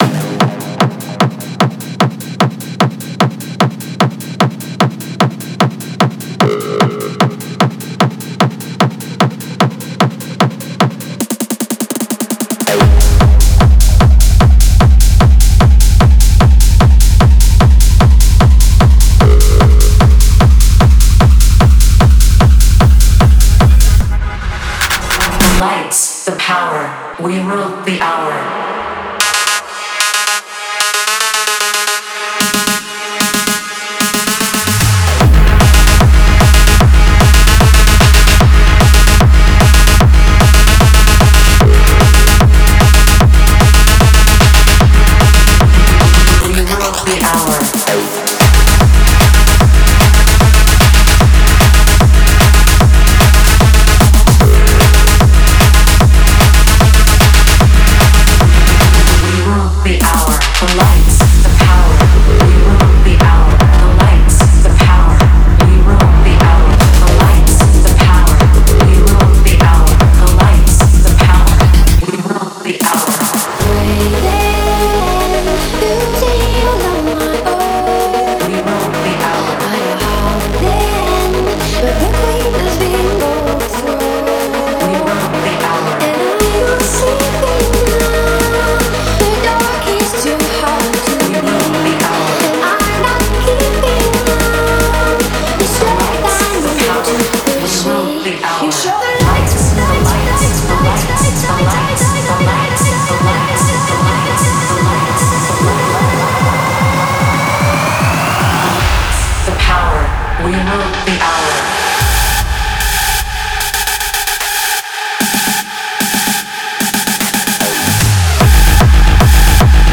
试听文件为低音质，下载后为无水印高音质文件 M币 15 超级会员 M币 8 购买下载 您当前未登录！